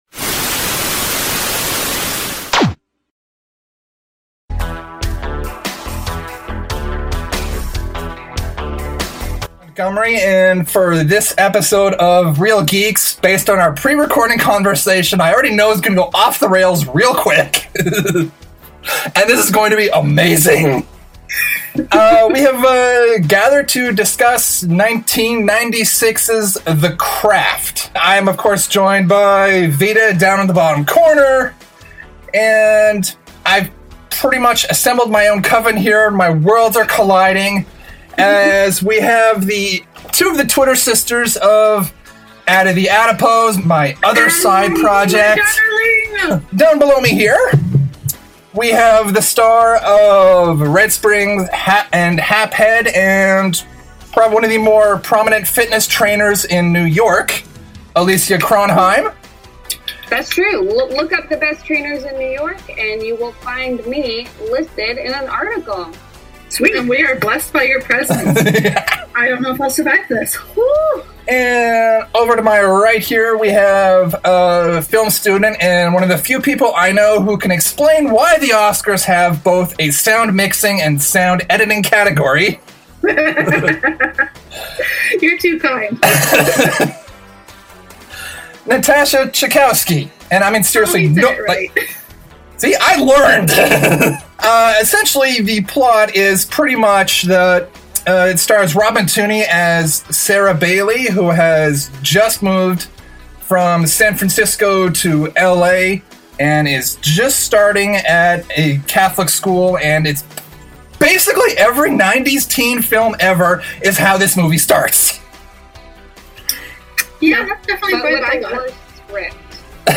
Note: For some reason the very beginning and very ending of the review were cut off.
Originally recorded in Halifax, NS, Canada
Video: Whereby Video Conferencing